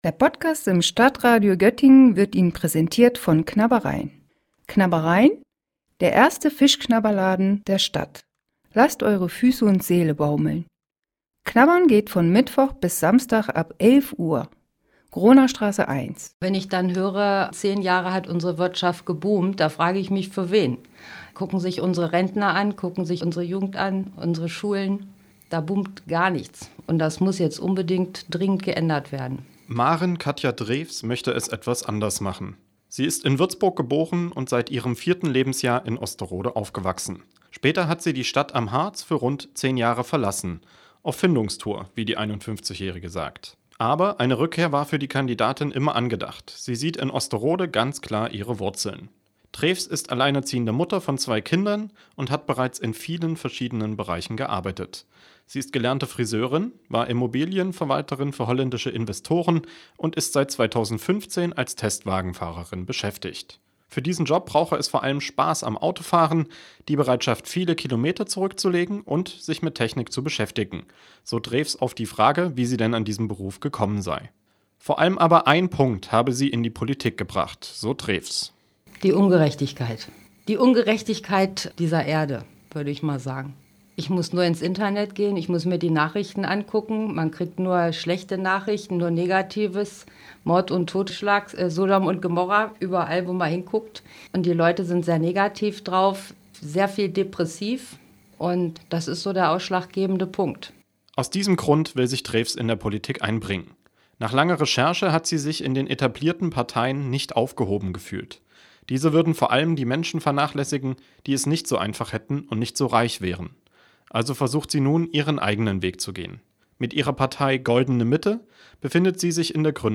O-Ton 1